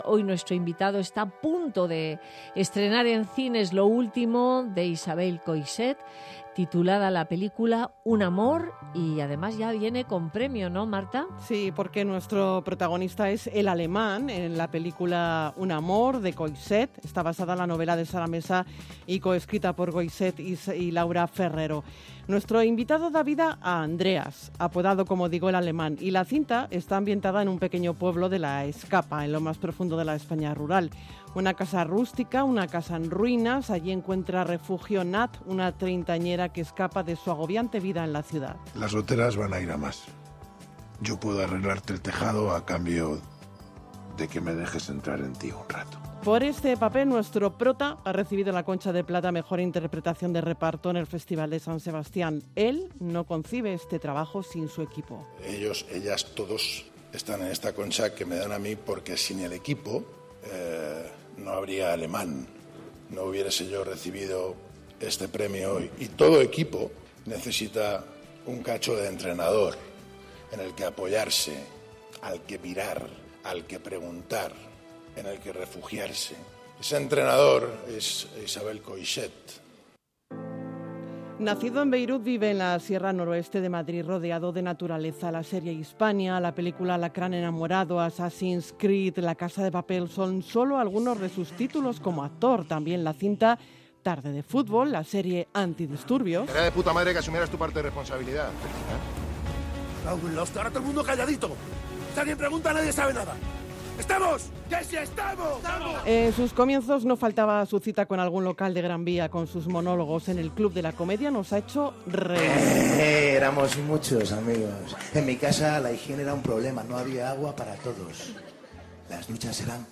Keuchkerian ha visitado Madrid Directo de Onda Madrid con Nieves Herrero.